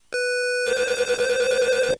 描述：带32mb卡和i kimu软件的gameboy样品